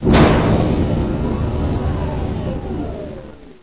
doormove1.wav